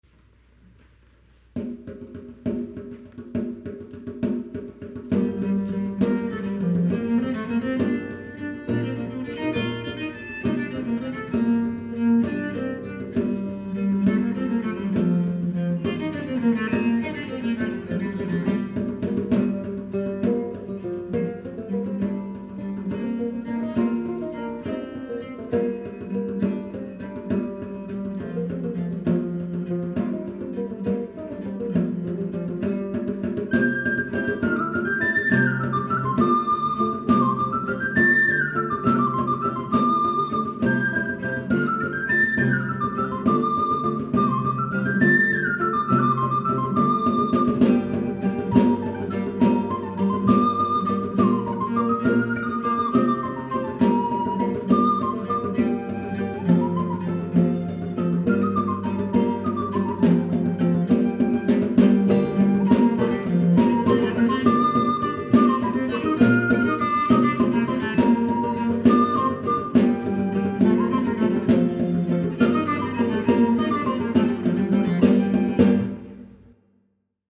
Chamber Consort 'Food of Love' 1983
viol
recorder
lute
drum
. transcribed from duple to the triple meter of the basse danse.
Suitable harmony has been added.